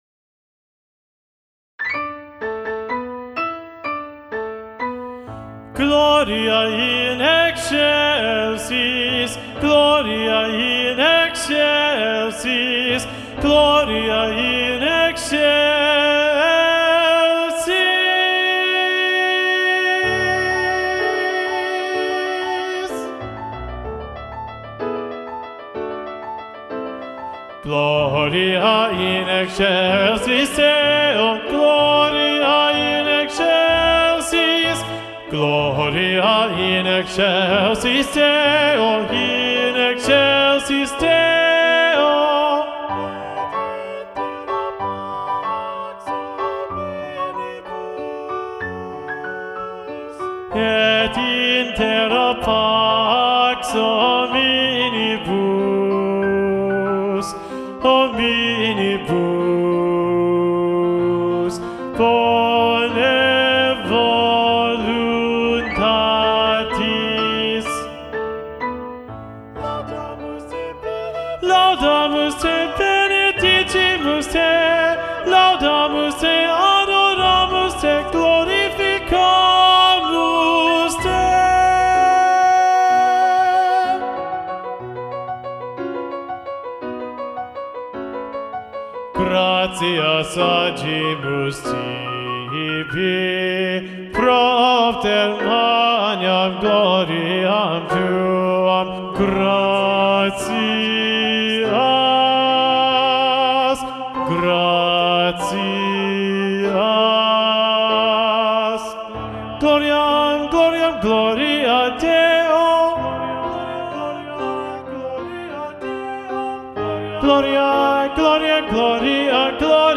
Lo ULTIMO Tenores
Gloria-Missa-Festiva-SATB-Tenor-Predominant-John-Leavitt.mp3